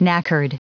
Prononciation du mot knackered en anglais (fichier audio)
Prononciation du mot : knackered